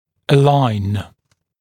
[ə’laɪn][э’лайн]выравнивать, ставить в ряд